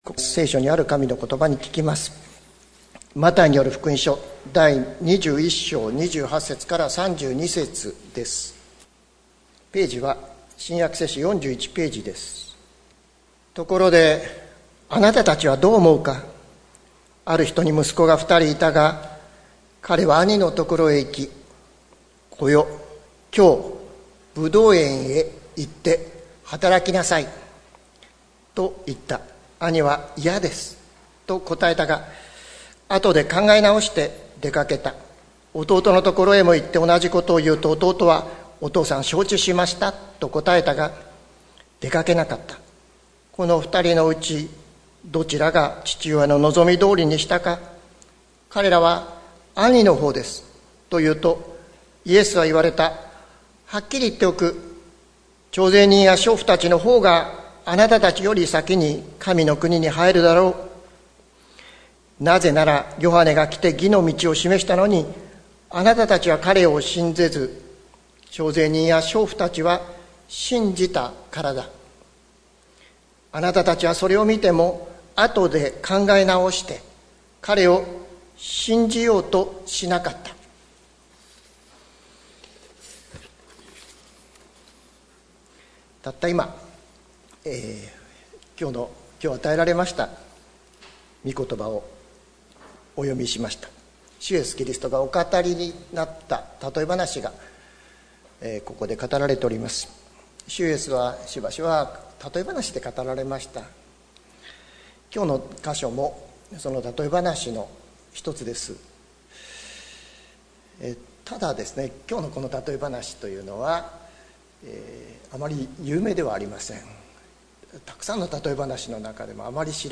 関キリスト教会。説教アーカイブ。